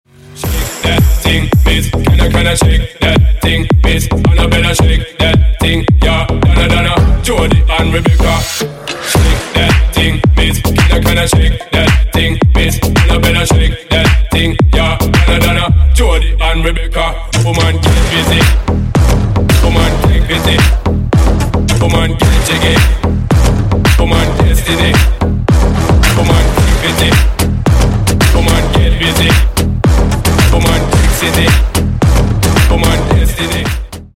Клубные Рингтоны » # Громкие Рингтоны С Басами
Рингтоны Ремиксы
Танцевальные Рингтоны